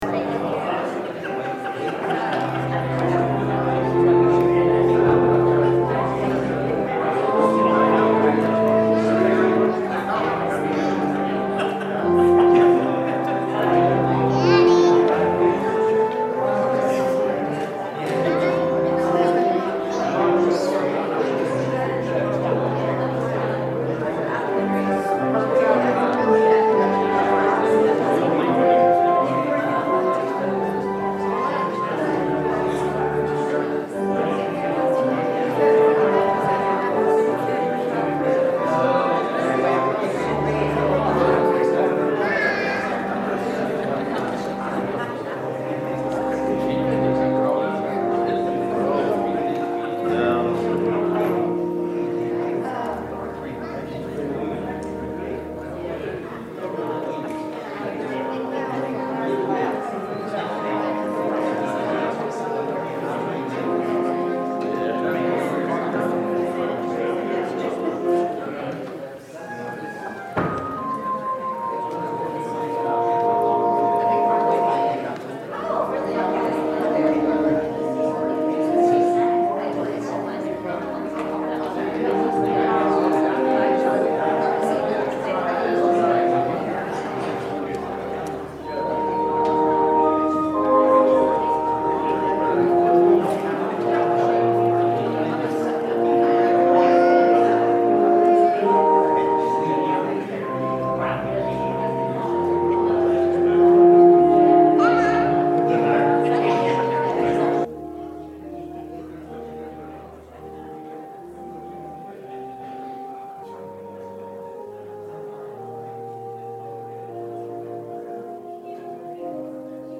31 Service Type: Sunday Worship Happy Mother's Day!